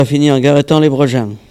Mémoires et Patrimoines vivants - RaddO est une base de données d'archives iconographiques et sonores.
Sallertaine
Locution